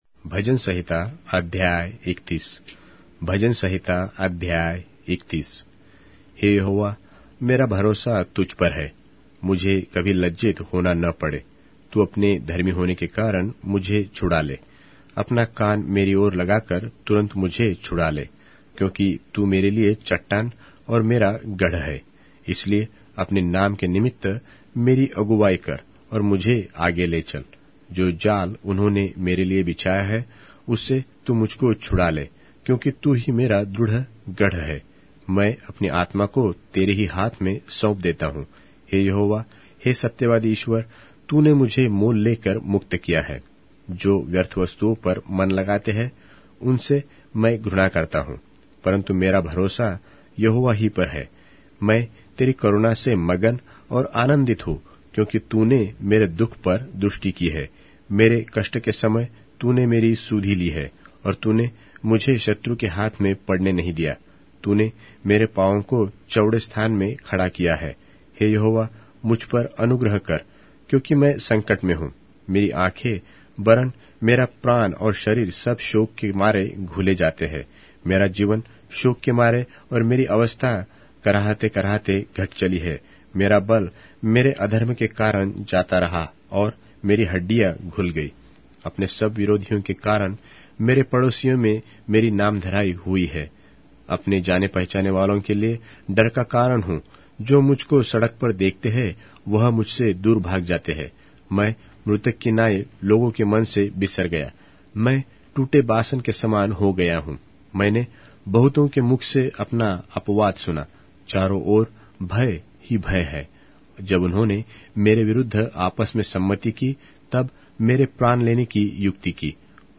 Hindi Audio Bible - Psalms 139 in Irvpa bible version